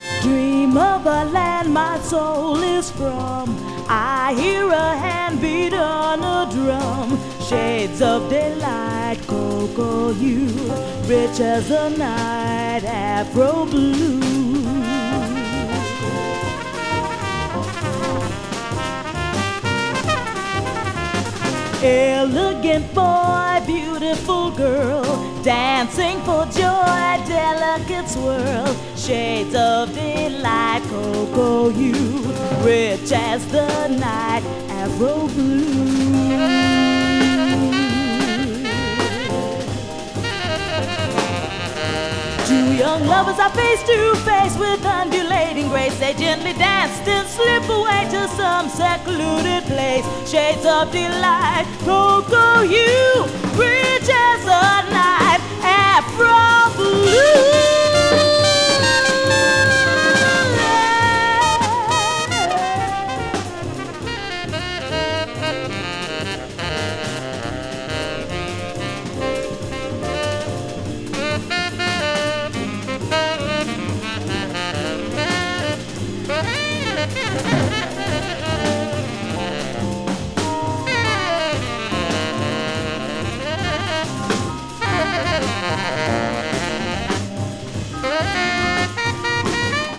media : EX/EX(some slightly noise.)
spiritual and groovy cover